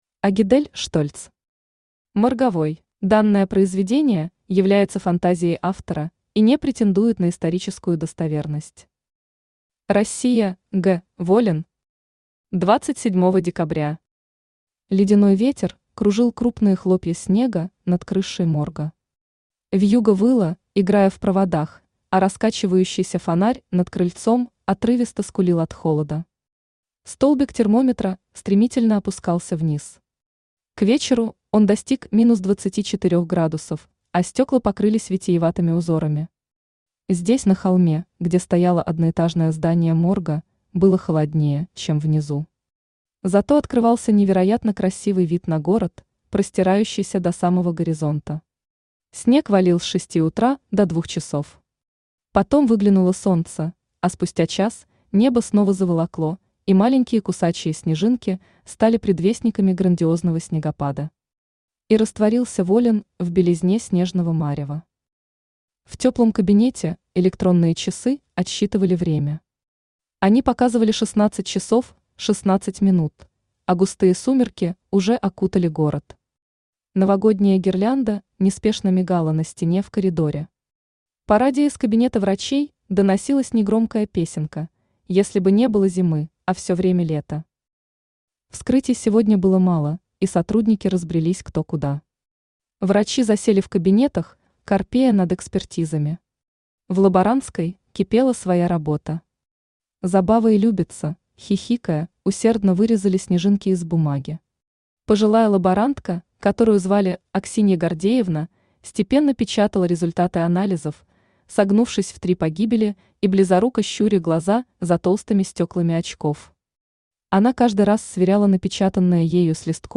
Aудиокнига Морговой Автор Агидель Штольц Читает аудиокнигу Авточтец ЛитРес.